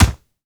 punch_general_body_impact_02.wav